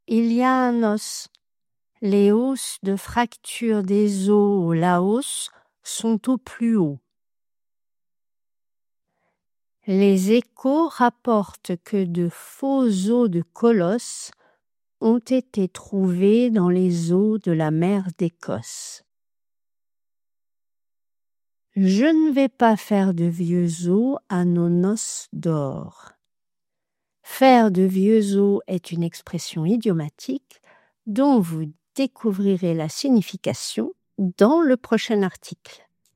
Au singulier : Un‿os     /œ̃nɔs/ avec une liaison en /n/  ; l’os   /lɔs/
Au pluriel :     Des‿os     /dezo/   ou /dɛzo/  avec une liaison en /z/
Saurez-vous lire correctement les deux phrases suivantes à voix haute (avec les bonnes liaisons) ?